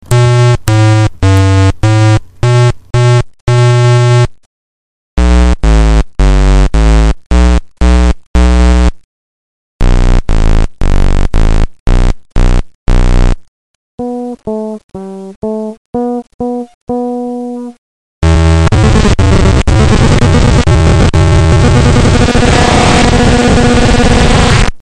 这个绿不拉几的玩音是个八度类的单块，用ZAKK的话说，它拥有放屁一样的声音！在4MS里它们叫噪音类效果器（也确实很噪音）